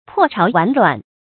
破巢完卵 注音： ㄆㄛˋ ㄔㄠˊ ㄨㄢˊ ㄌㄨㄢˇ 讀音讀法： 意思解釋： 見「破巢馀卵」。